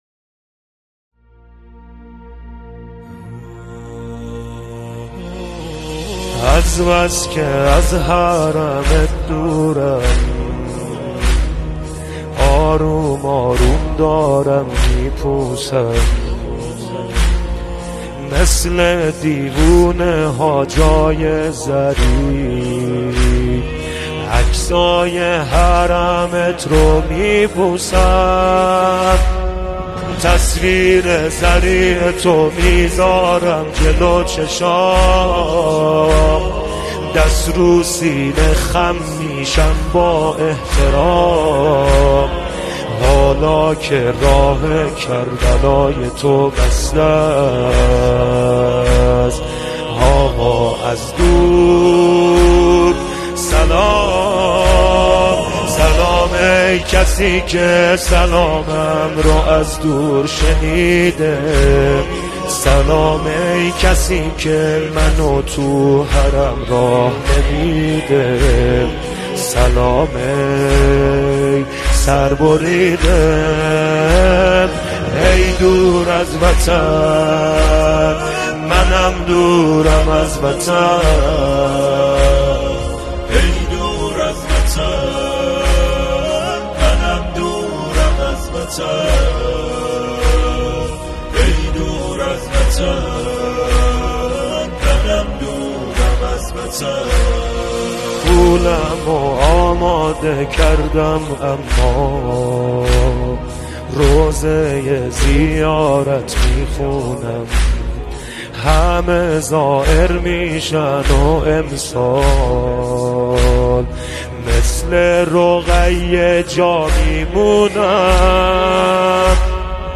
نوحه ترکی
نوحه لری، نوحه آذری